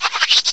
cry_not_wimpod.aif